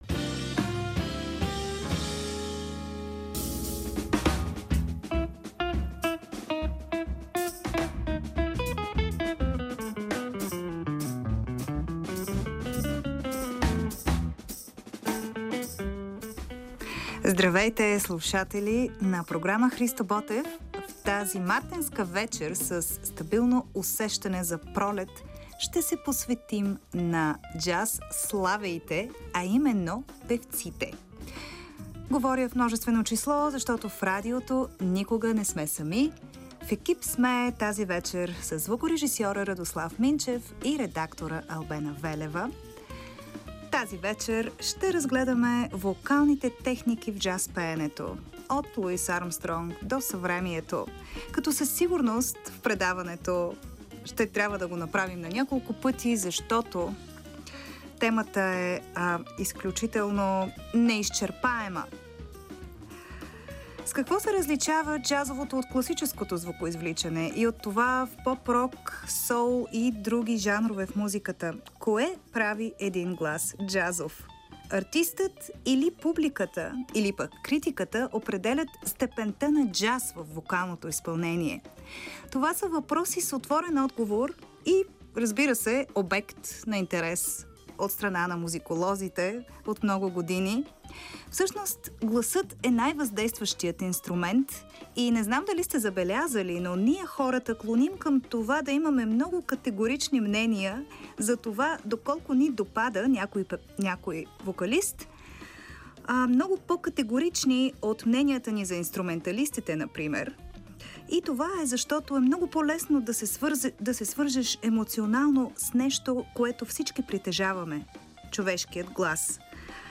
Ще потърсим отговори на тези въпроси с помощта на песни (стандарти), записани от емблематични певци със статут на легенди, които са повлияли повечето съвременни джаз вокалисти.